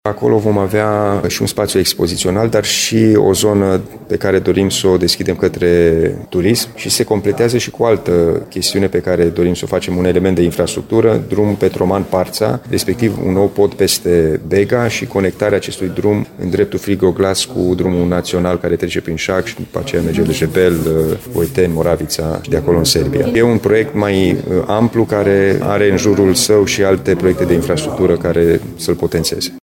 Urmează finalizarea conceptului și identificarea sursei de finanțare, spune președintele administrației județene, Alin Nica.
Parcul se va armoniza cu viitorul muzeu care se va deschide la Parța, mai spune Alin Nica.